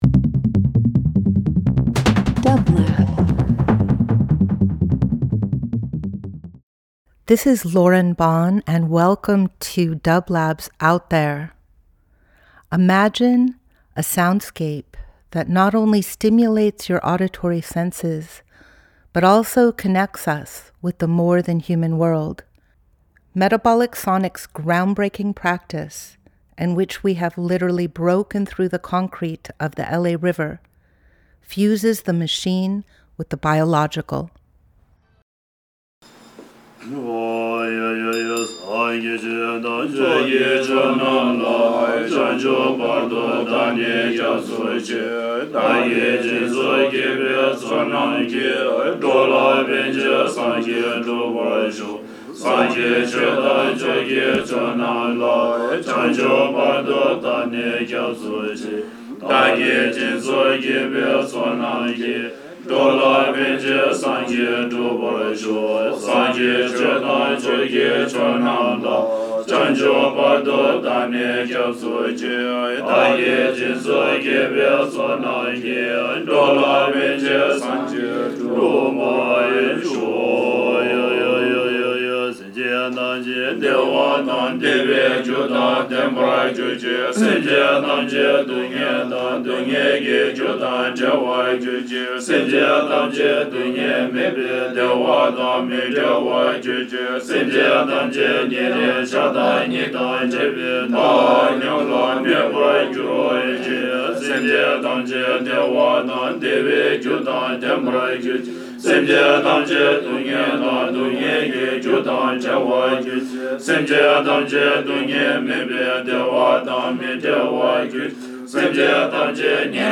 Each week we present field recordings that will transport you through the power of sound.
Metabolic Sonics Metabolic Studio Out There ~ a field recording program 01.15.26 Ambient Experimental Field Recording Voyage with dublab into new worlds.
These are the Monks of Gaden Shartse Phukang Tibetan Sacred Harmony chanting the dissolution ceremony of the sand mandala they created in Lone Pine 2025.